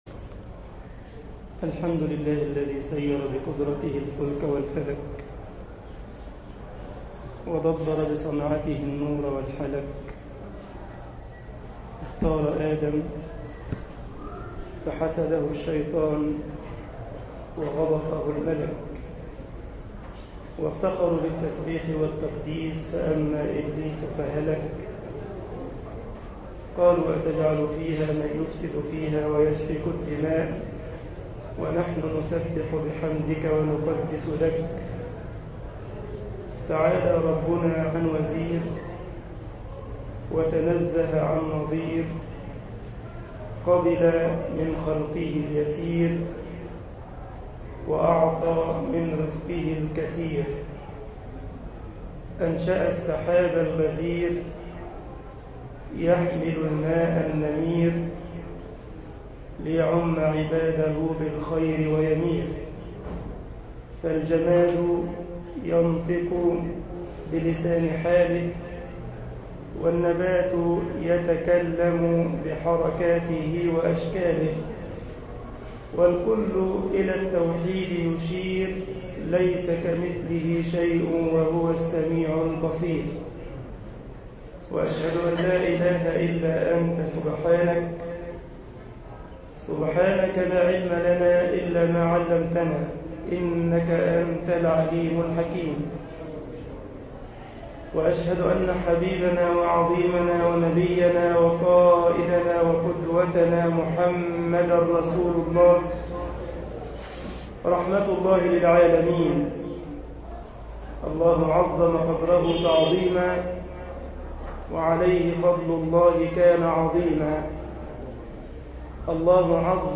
مسجد غمرة المنوفي ـ الشرابية ـ القاهرة